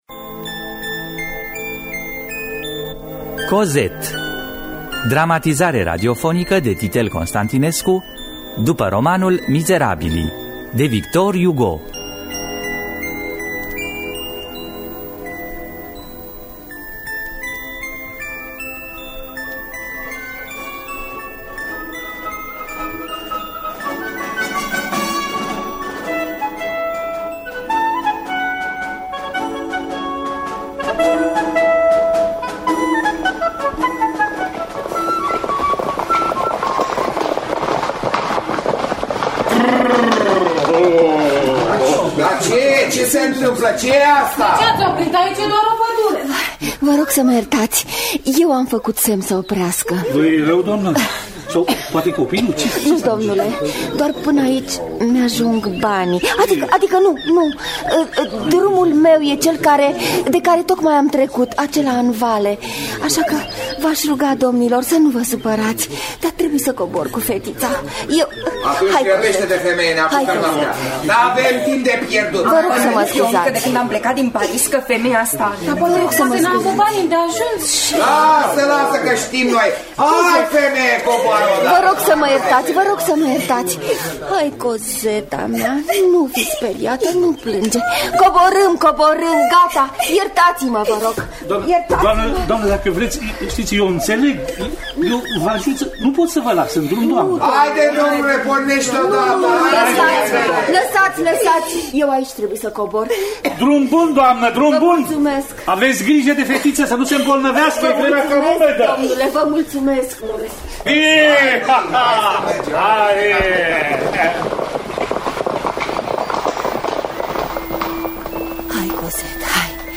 Dramatizare de Titel Constantinescu după Mizerabilii de Victor Hugo.